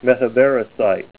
Help on Name Pronunciation: Name Pronunciation: Metavariscite + Pronunciation